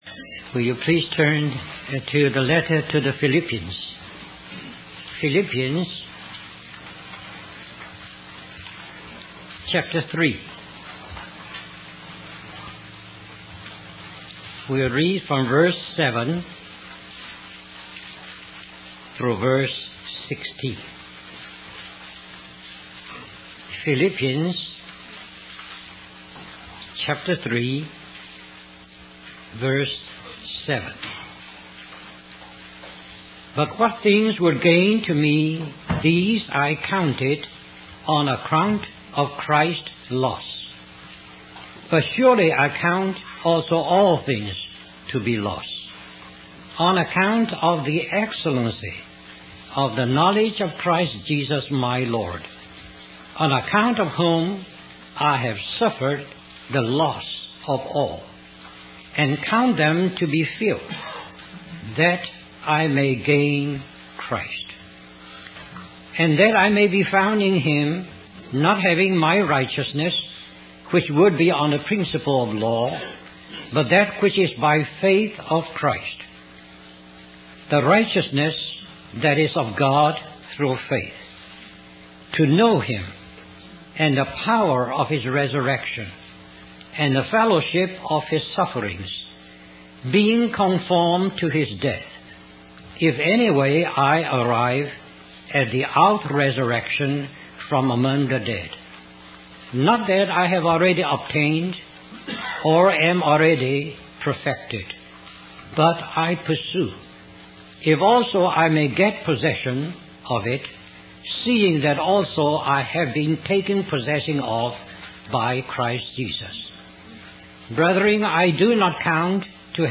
In this sermon, the preacher recounts the story of Saul's encounter with Jesus on the road to Damascus.